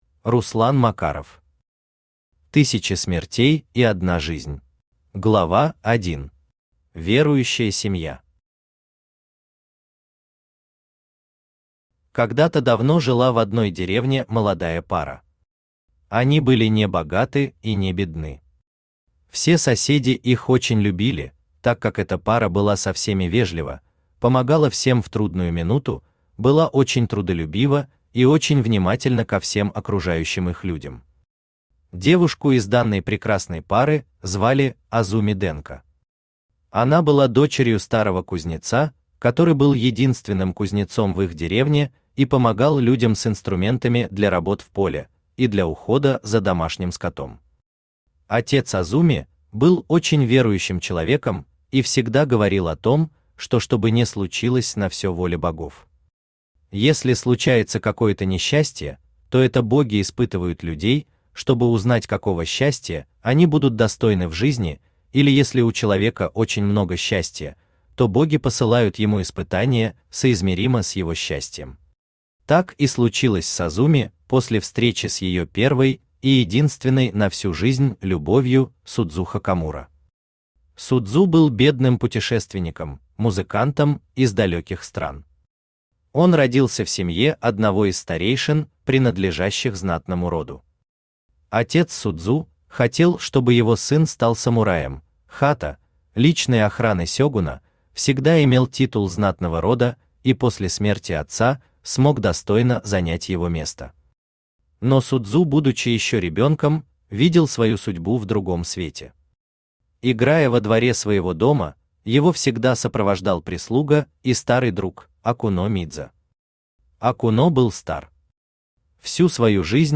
Аудиокнига Тысяча смертей и одна жизнь | Библиотека аудиокниг
Aудиокнига Тысяча смертей и одна жизнь Автор Руслан Макаров Читает аудиокнигу Авточтец ЛитРес.